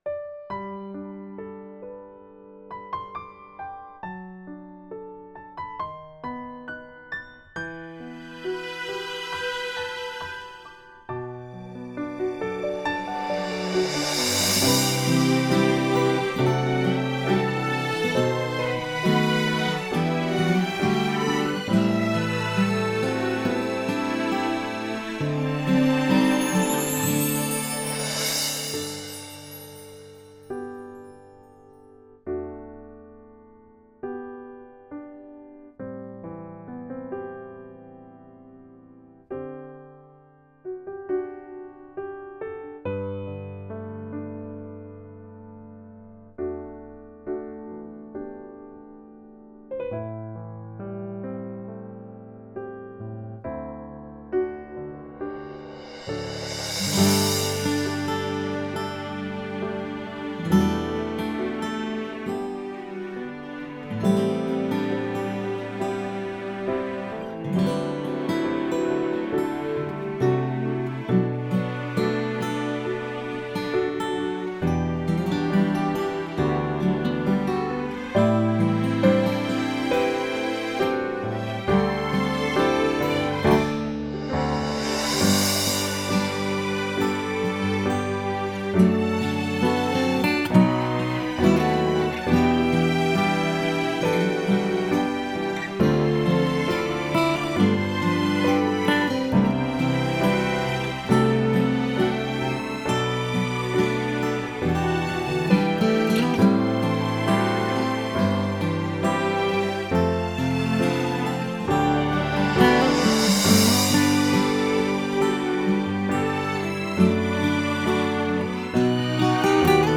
mp3 伴奏音樂